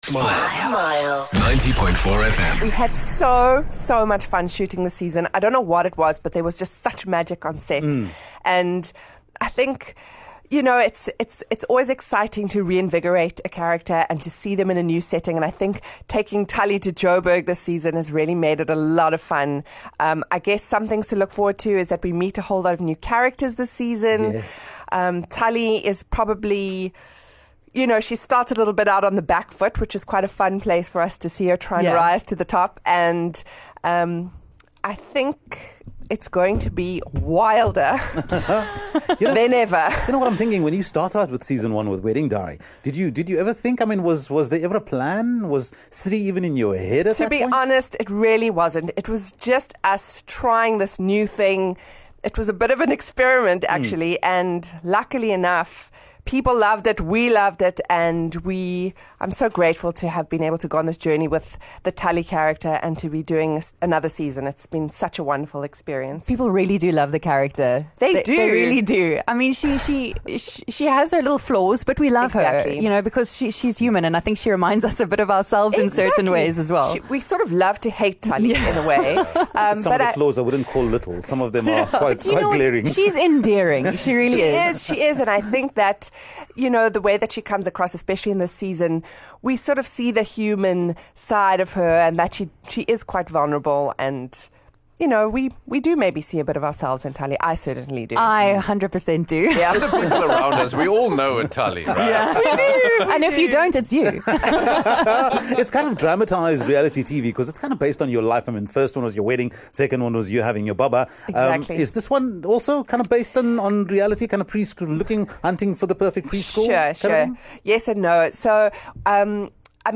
Having shared her wedding in Tali’s Wedding Diary and then her pregnancy in Tali’s Baby Diary, Tali moves back to Joburg in Season 3, determined to get her son Jayden into the best preschool around, and reclaim her crown, or at the very least the title of Class Mom. Star of the show Julia Anastasopoulos joined Smile Breakfast for a chat about the new season - Tali's Joburg Diary: